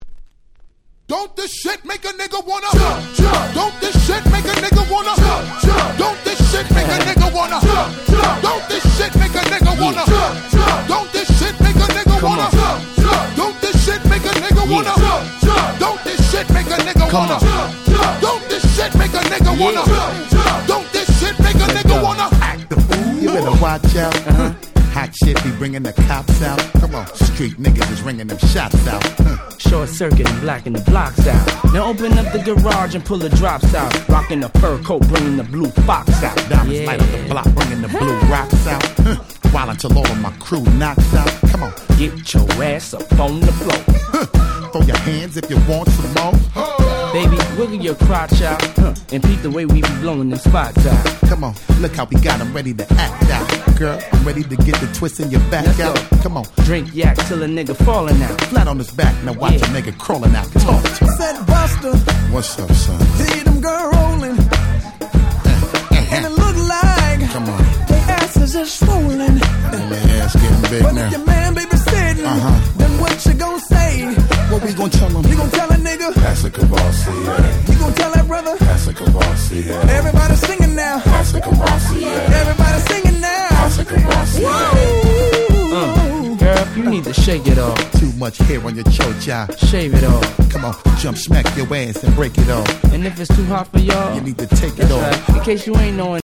02's Big Hit Hip Hop !!